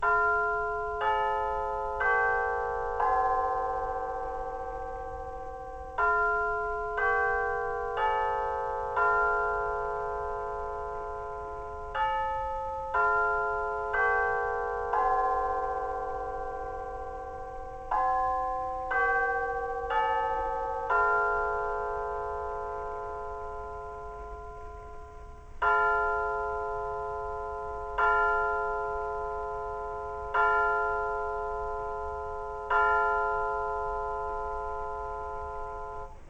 *Tublar chime sound reminiscent of a Grandfather clock.
chime_westminster.mp3